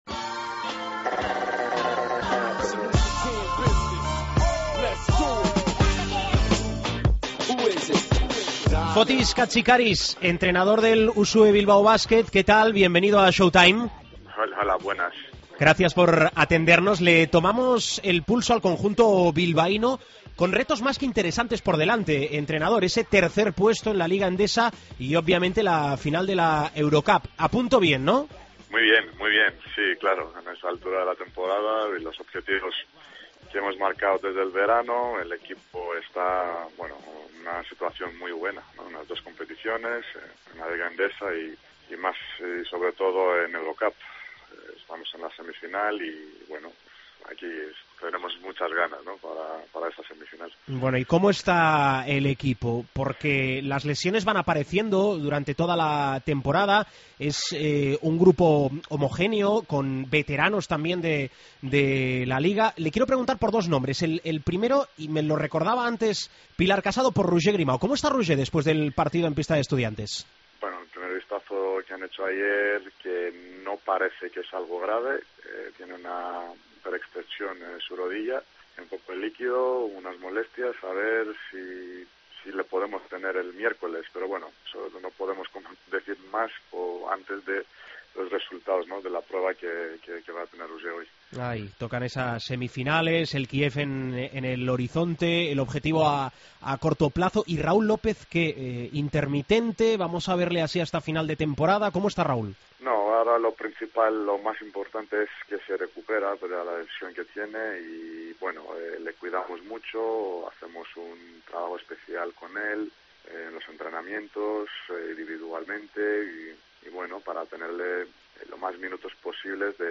Entrevista a Fotis Katsikaris, en Showtime